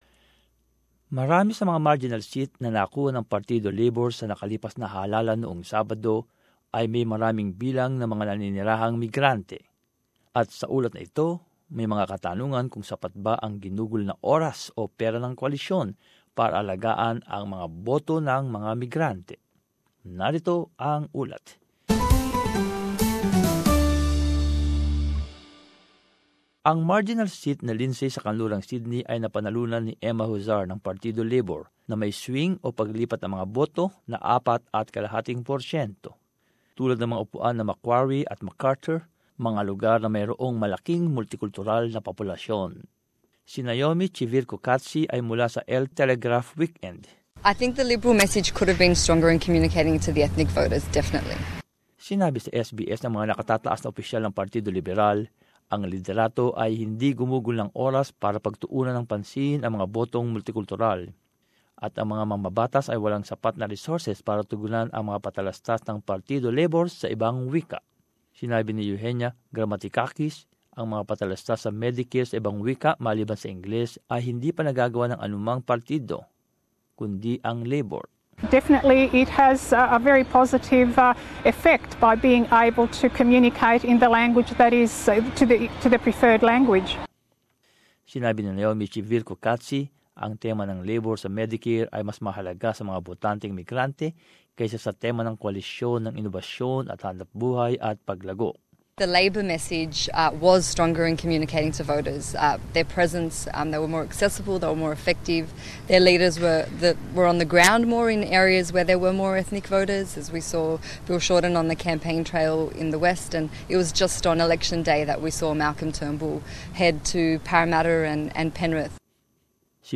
And as this report shows, questions are being asked about whether the coalition spent enough time or money cultivating the migrant vote.